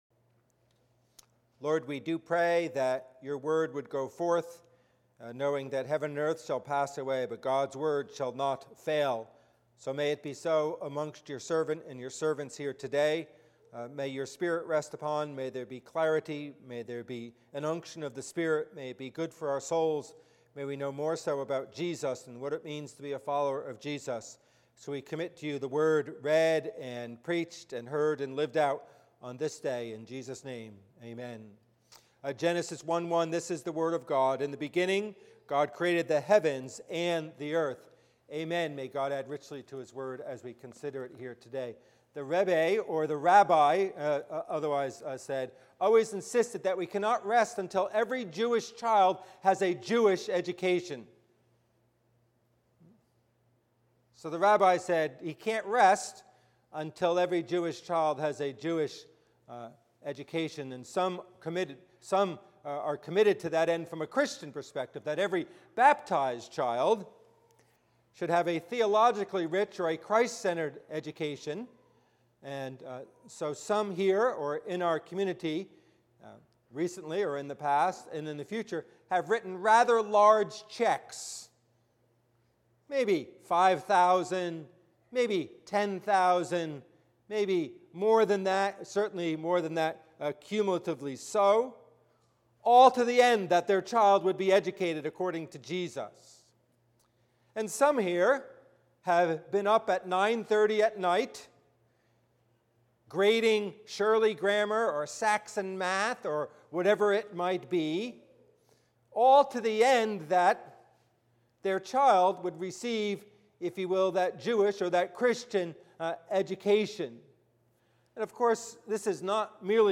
Passage: Genesis 1:1 Service Type: Worship Service